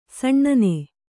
♪ saṇṇane